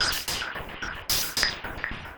Index of /musicradar/rhythmic-inspiration-samples/110bpm
RI_RhythNoise_110-04.wav